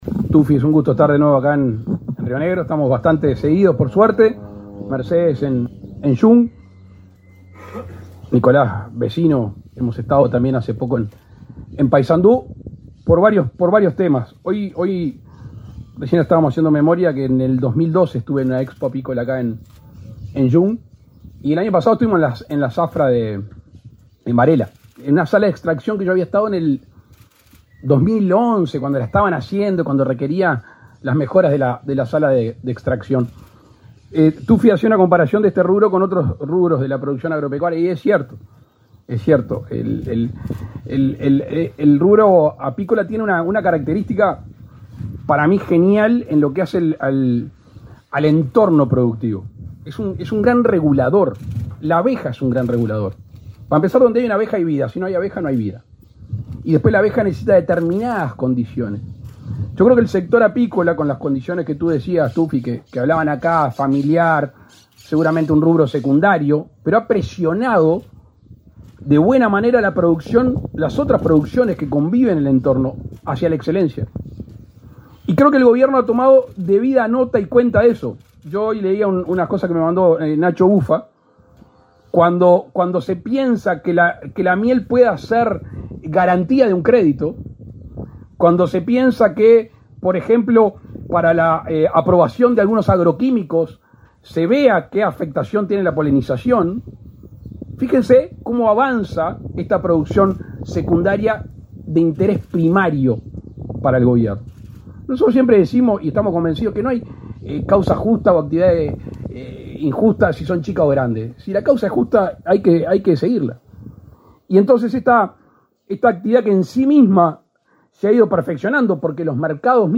Palabras del presidente de la República, Luis Lacalle Pou
Con la presencia del presidente de la República, Luis Lacalle Pou, se realizó, este 20 de octubre, un acto por la apertura oficial de la zafra de miel